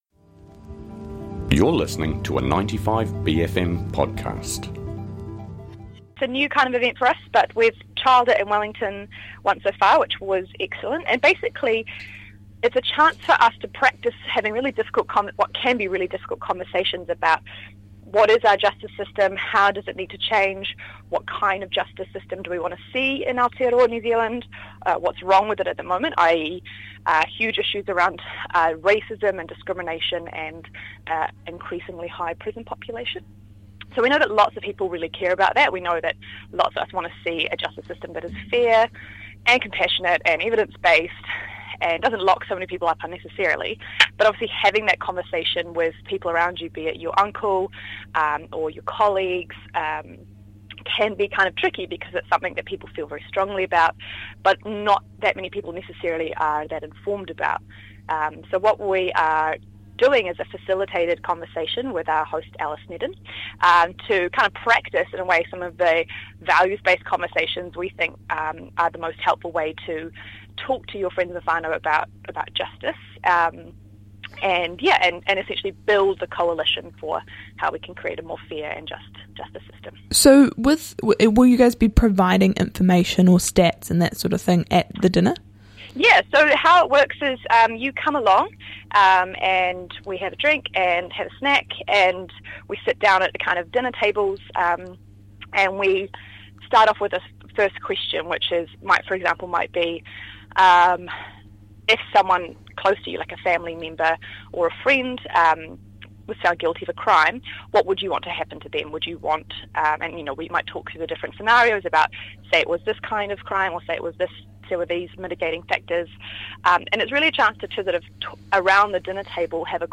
I/V w/ JustSpeak on transformational change in the justice system: May 8, 2019